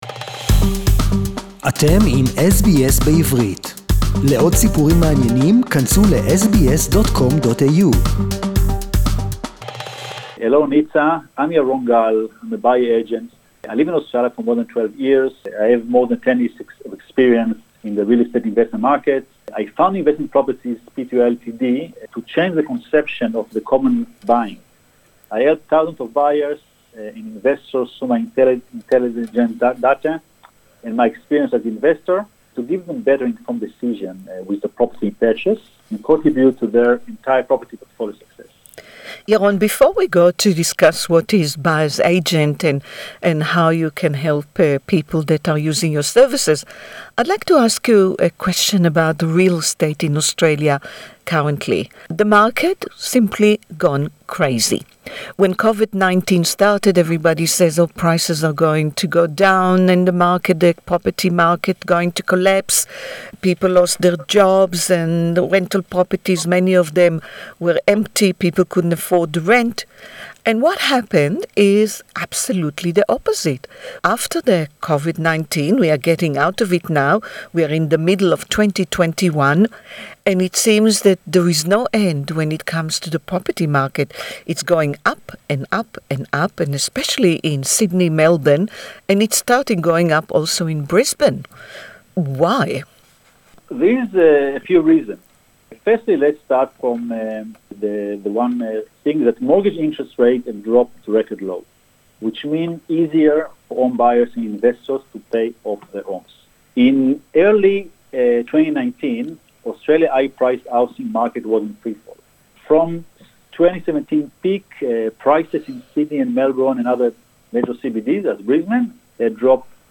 a Buyers’ agent to find out why prices are so high and where and when should one start looking to buy property (English interview) Share